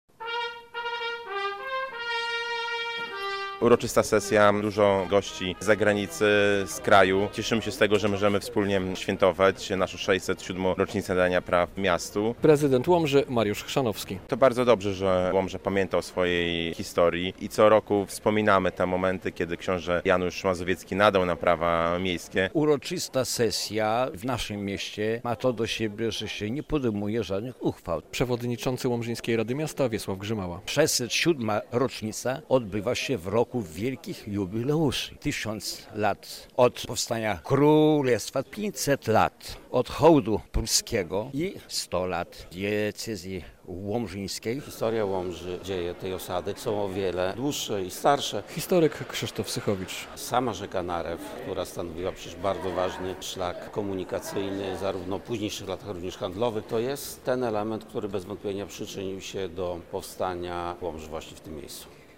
Uroczysta sesja łomżyńskich radnych - relacja